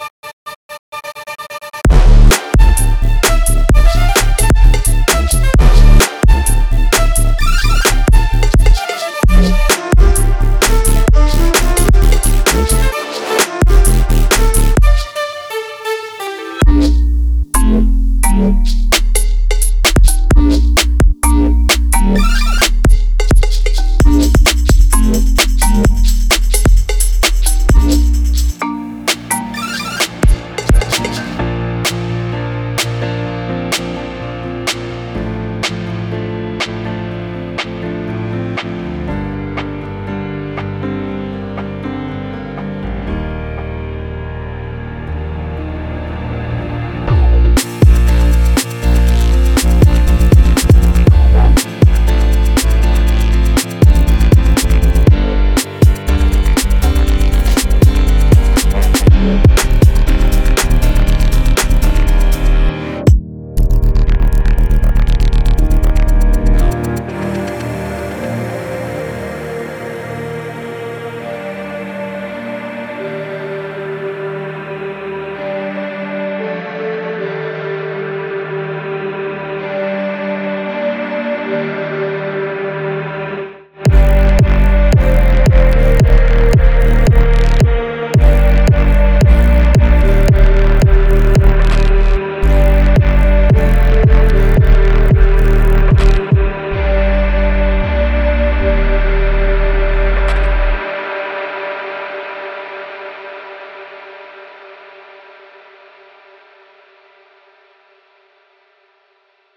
生き生きとした演奏の温もりを取り入れ、ダイナミックでテクスチャー豊かなグルーヴの中心に配置しています。
それは日の出の街のサウンドであり、ポジティブな振動と高揚感あふれるハーモニーを持つ、完璧なバランスです。
一般的なビートから離れ、テクスチャー豊かでシンコペーションが効いた「生きた」グルーヴを追求しています。
24種類のフルドラムループとドラムフィルセットは、電子制作のパンチとアコースティックドラムの空気感を融合させます。
これにより、洗練されたポッププロダクションに最適な、ポジティブで催眠的なリズムが生まれます。
Genre:Indie Pop
92 - 146 BPM